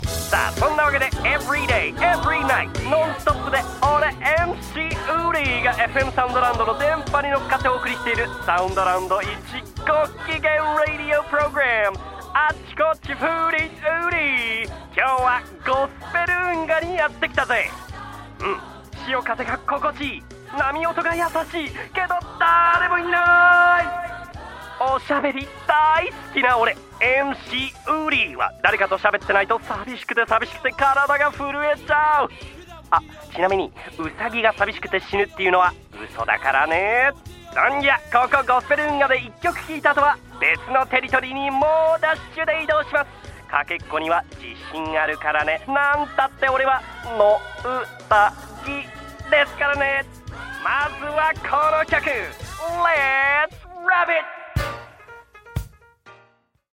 Experience exceptional bilingual narration and live announcing, seamlessly blending English and Japanese to captivate global audiences.
VOICE SAMPLE